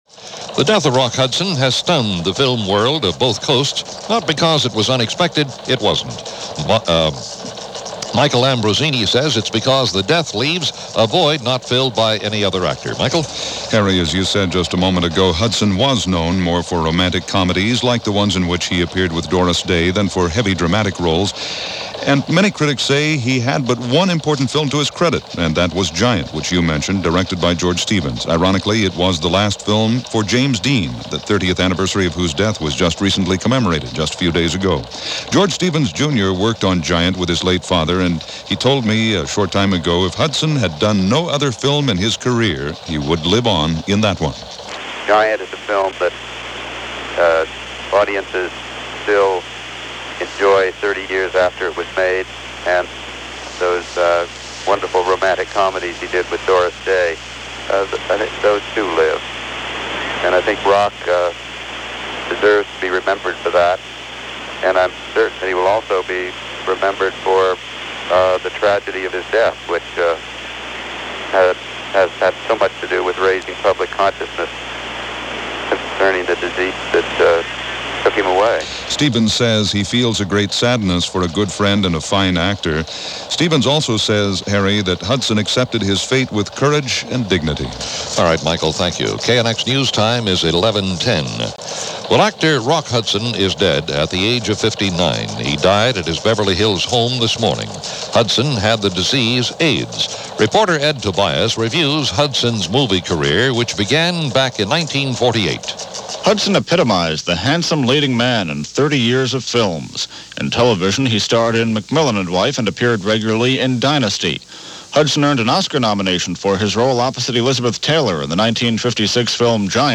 October 2, 1985 - AIDS comes To Hollywood - Death Of Rock Hudson - news for this day in history - 1985 as presented by CBS Radio News.
– CBS News Reports and The World Tonight – October 2, 1985 – Gordon Skene Sound Collection –